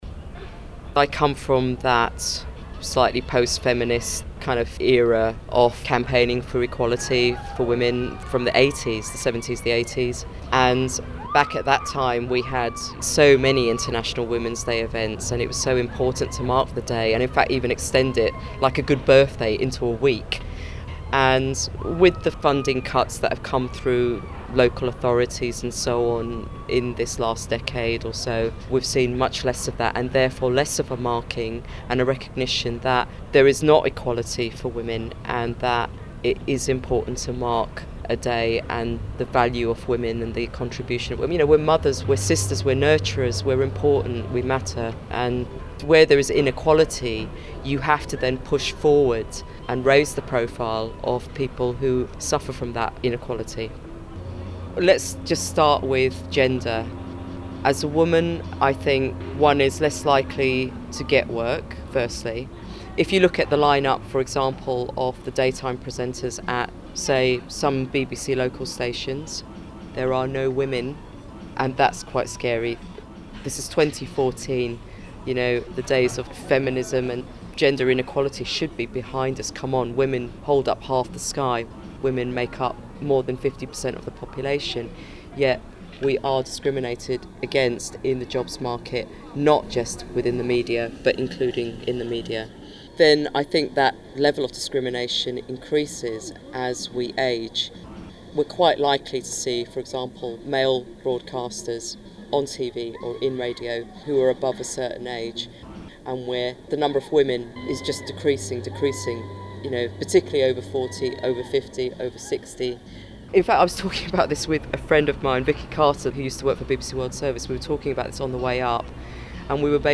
This year, we spent International Women’s Day with the Ladies of Fem FM, celebrating the launch of their new online archive.